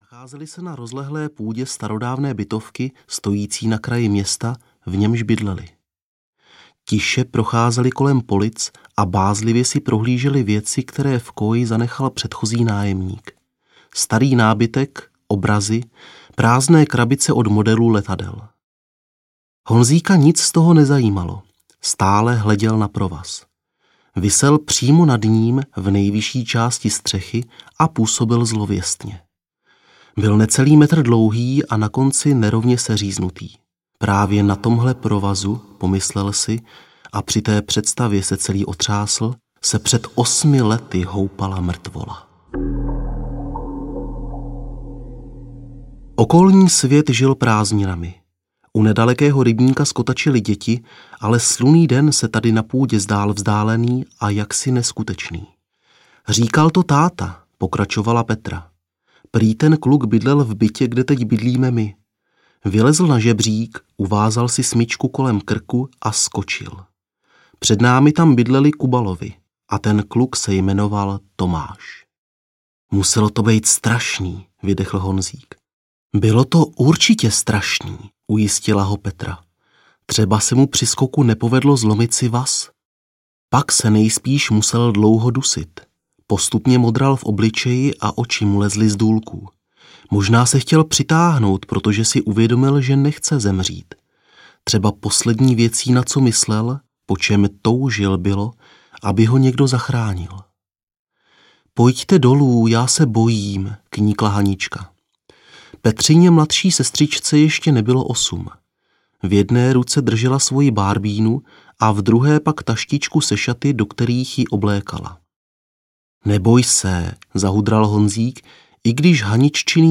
Hororové povídky audiokniha
Ukázka z knihy